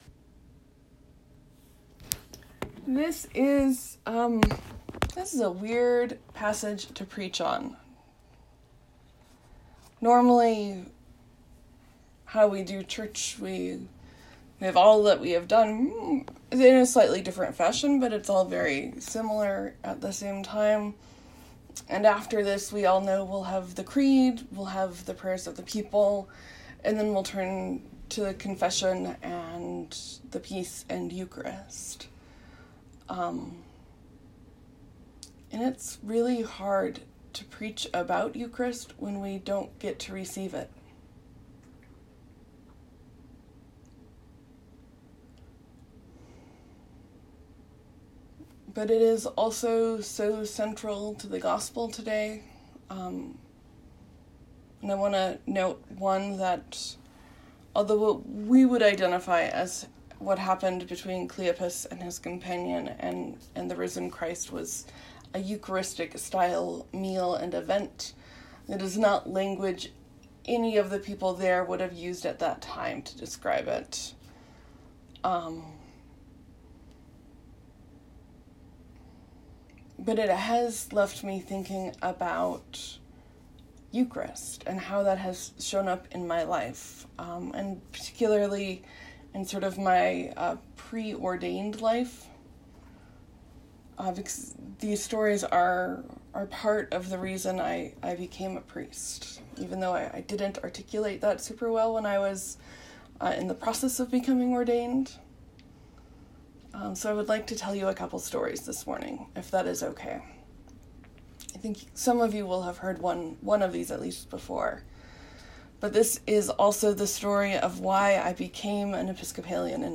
It’s hard to preach on Eucharist when we aren’t celebrating Eucharist. I couldn’t come up with anything more true than what I’ve lived, so I shared a couple of those stories and reflected on the Gospel text briefly.